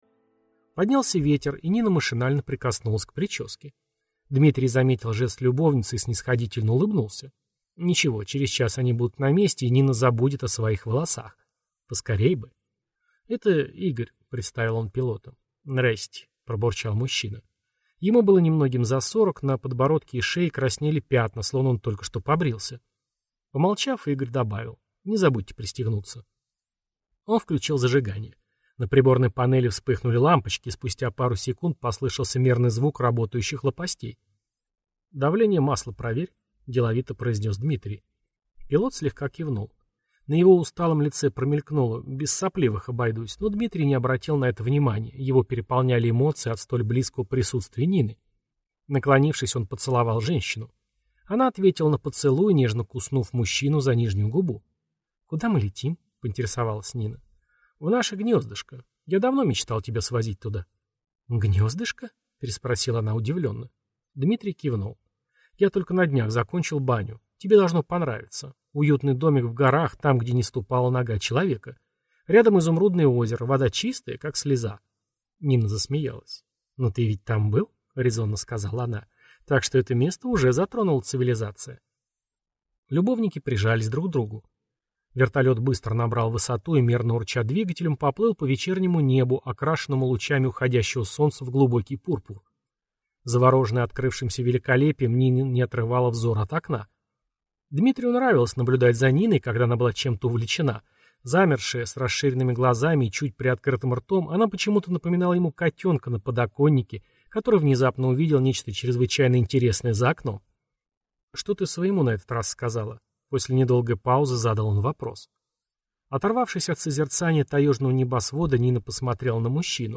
Аудиокнига Неадекват | Библиотека аудиокниг
Прослушать и бесплатно скачать фрагмент аудиокниги